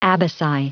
Prononciation du mot abaci en anglais (fichier audio)
Prononciation du mot : abaci